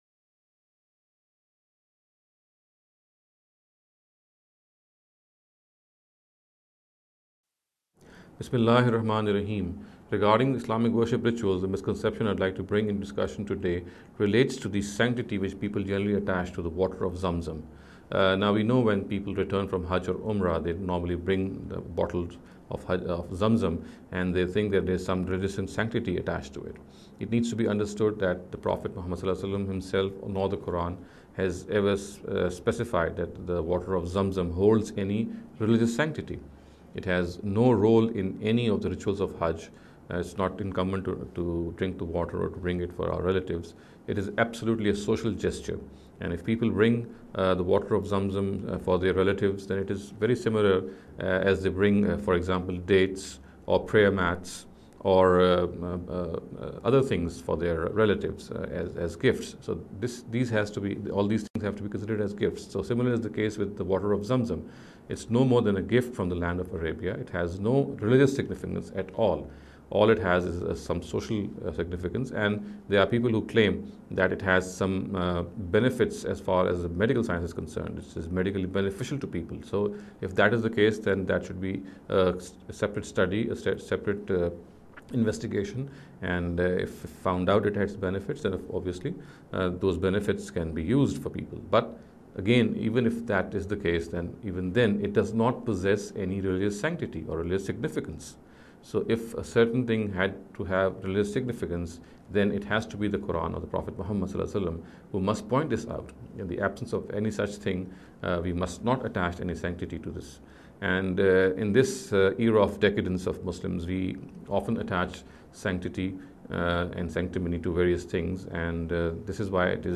This lecture series will deal with some misconception regarding the Islamic Worship Rituals.